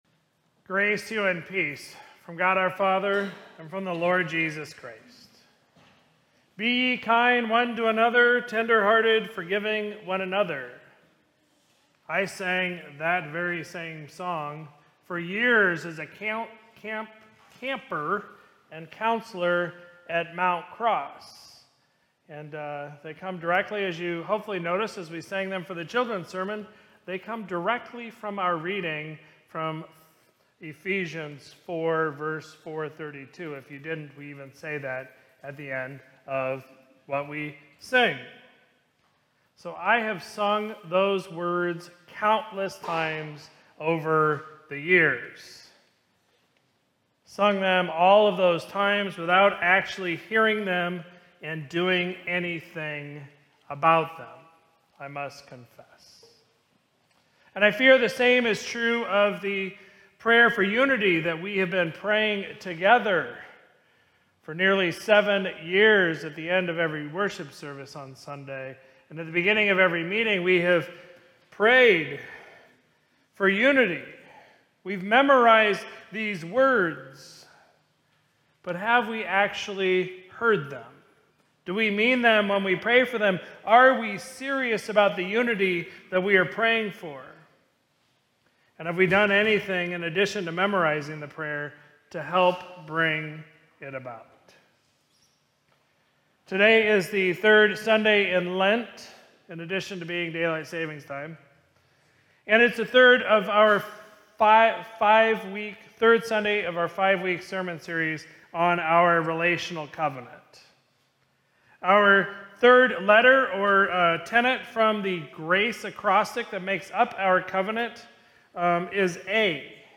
Sermon from Sunday, March 8, 2026